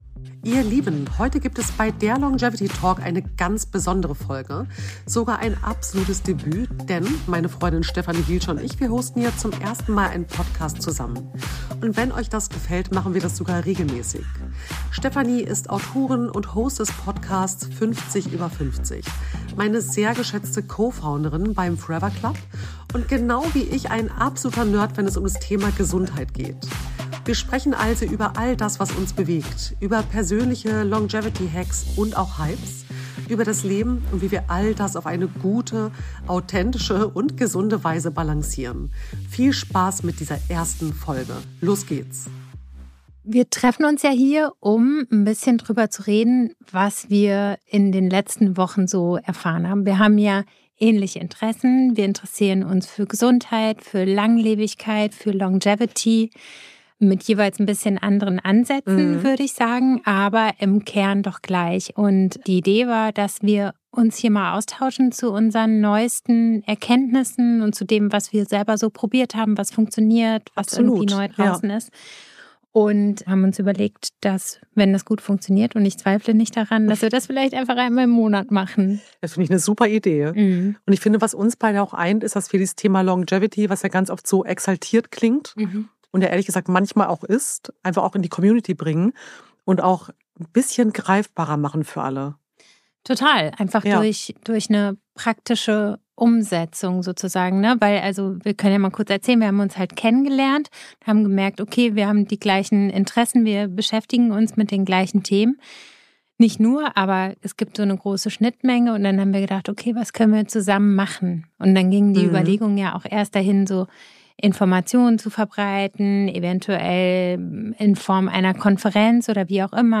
Ein ehrliches Gespräch über Gesundheit, Wachstum, Grenzen (setzen!) und warum „langsamer machen“ oft der schnellere Weg ist.